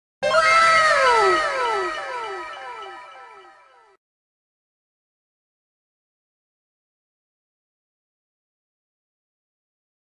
ghbot/sfx/wow.mp3 at a88b397f12730f1c730f79bec3ce34b7d878c164
wow.mp3